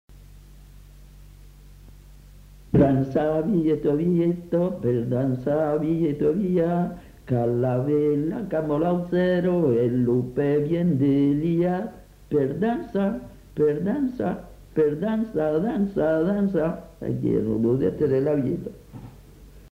Aire culturelle : Haut-Agenais
Genre : chant
Effectif : 1
Type de voix : voix d'homme
Production du son : chanté
Danse : bigue-biguette